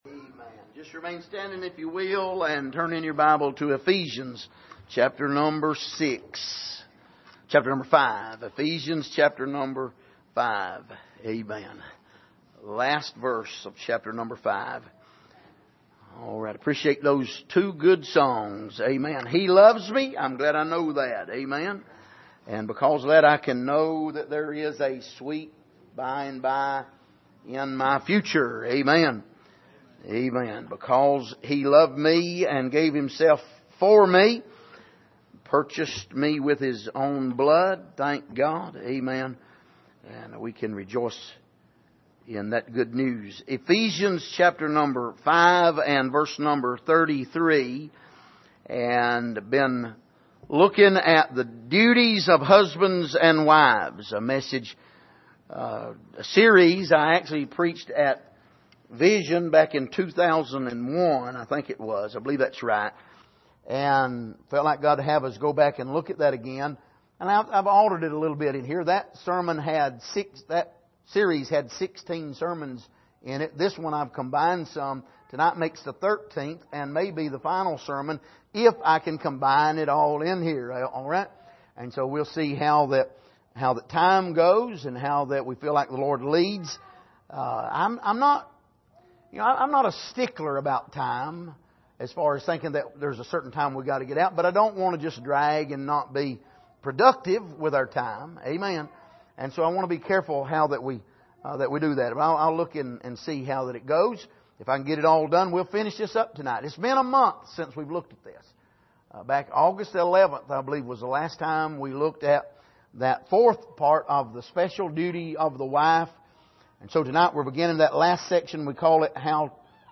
Here is an archive of messages preached at the Island Ford Baptist Church.